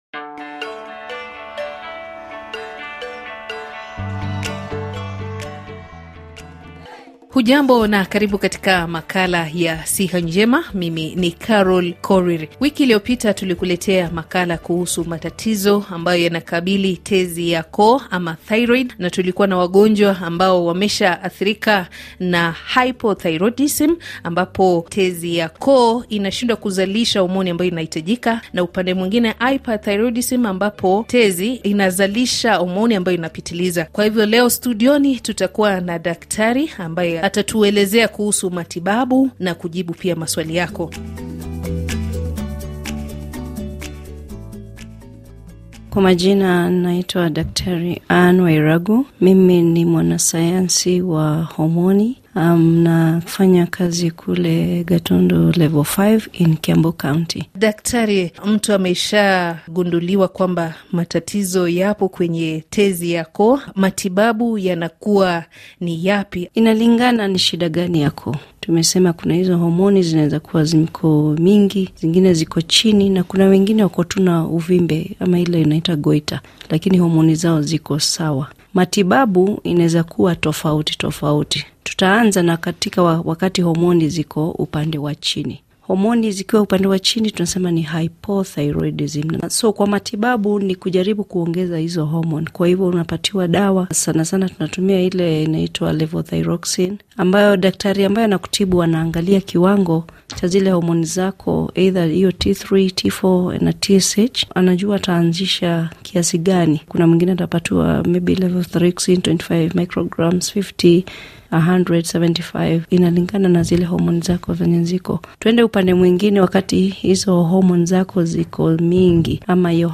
Makala inayojadili kwa kina masuala ya afya, tiba na kutambua magonjwa mbalimbali bila ya kusahau namna ya kukabiliana nayo. Watalaam walibobea kwenye sekta ya afya watakujuvya na kukuelimisha juu ya umuhimu wa kuwa na afya bora na si bora afya. Pia utawasikiliza kwa maneno yao watu ambao wameathirika na magonjwa mbalimbali na walikumbwa na nini hadi kufika hapo walipo.